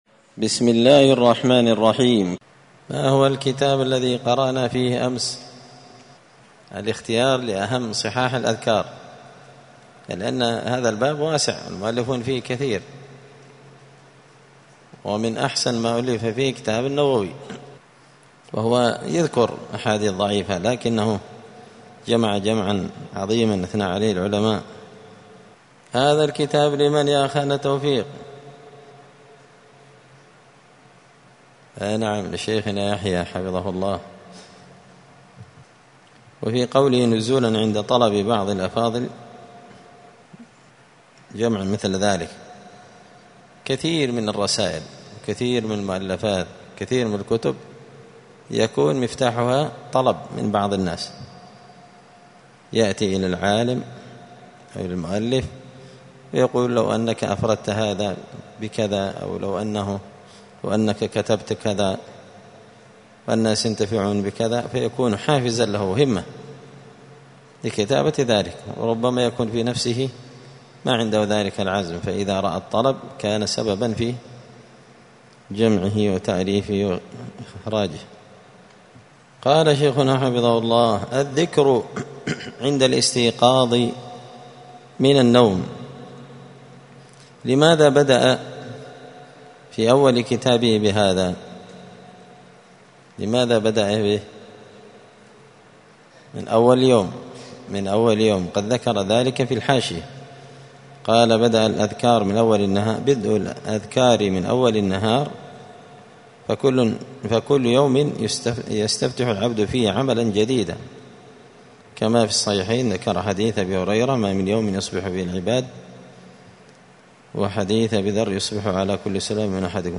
*{الدرس الدرس الثاني (2) الذكر عند الاستيقاظ من النوم وعند لبس الثوب}*